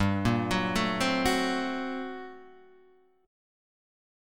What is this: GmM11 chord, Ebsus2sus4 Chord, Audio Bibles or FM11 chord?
GmM11 chord